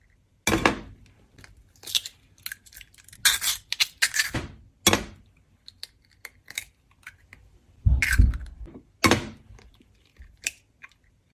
Tiếng Đập quả trứng rồi Bóc vỏ và Đổ ra
Tiếng Đập trứng, Bóc vỏ, Đổ ra nhiều lần… Âm thanh trả lời Đúng, Chính Xác
Thể loại: Tiếng ăn uống
Description: Âm thanh đặc trưng của tiếng đập quả trứng vào thành tô, tách, bụp.... vang lên rõ ràng, ngay sau đó là tiếng bóc vỏ trứng khẽ lách tách, rụp rụp, và cuối cùng là tiếng đổ trứng ra tô – sột soạt, lép nhép, nghe rất sống động. Tất cả hòa quyện với tiếng động lách cách trong bếp, tạo nên một hiệu ứng âm thanh chân thực cho các video nấu ăn.
tieng-dap-qua-trung-roi-boc-vo-va-do-ra-www_tiengdong_com.mp3